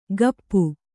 ♪ gappu